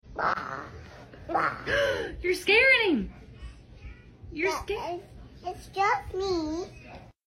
Woof Woof Bark Bark 🐶 Sound Effects Free Download